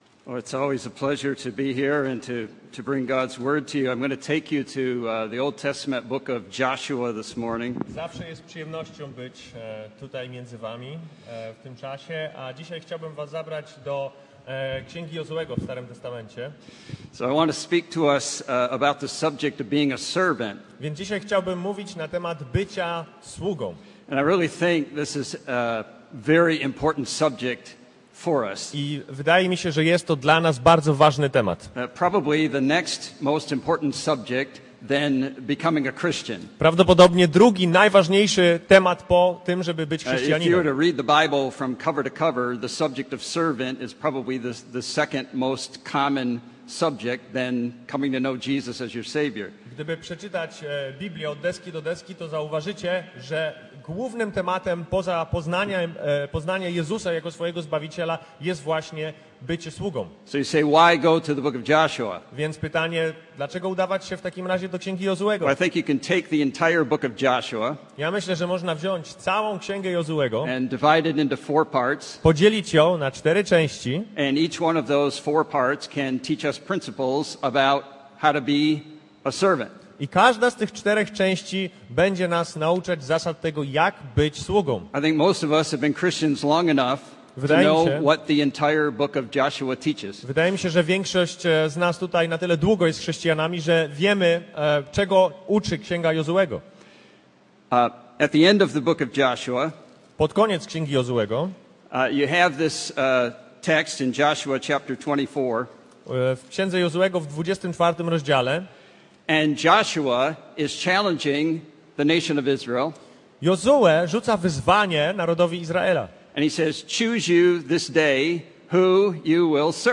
23.06.2024 Serce sługi Kaznodzieja